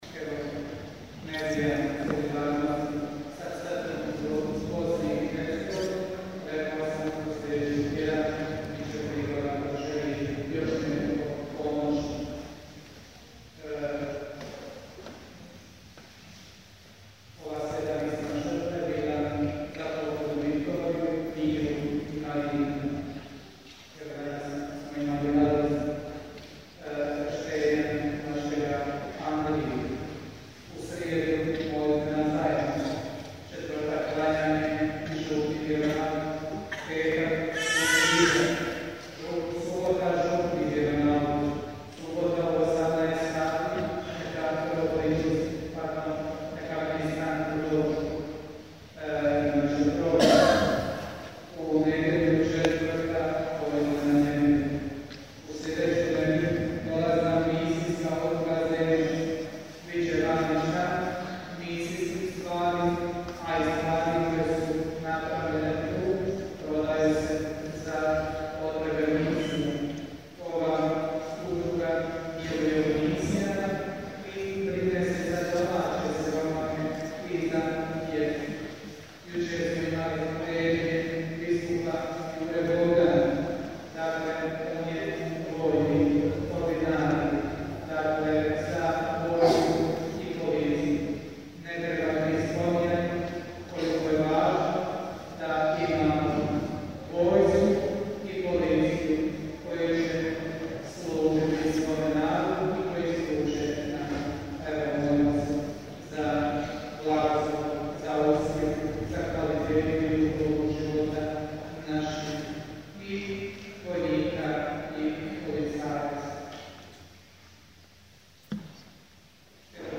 OGLASI I OBAVIJESTI :